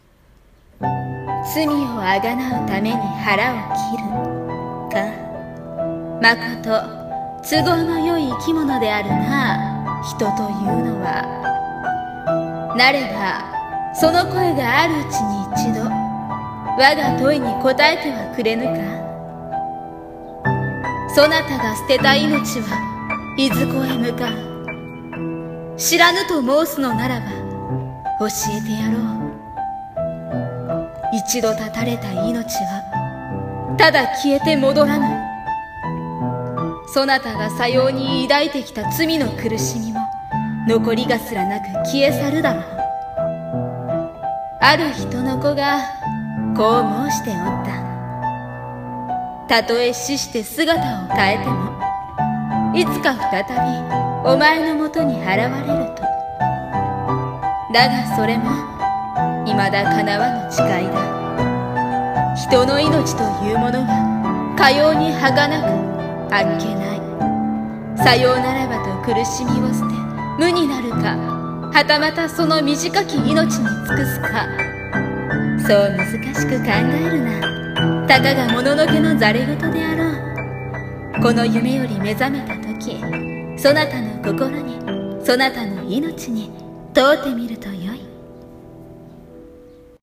【和風台本】命に問う【一人声劇】
ピアノ